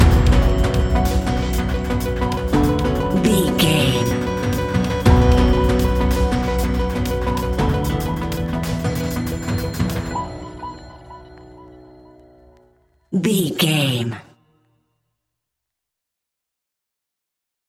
A great piece of royalty free music
In-crescendo
Thriller
Aeolian/Minor
ominous
dark
eerie
mysterious
electronic music
Horror Pads
Horror Synths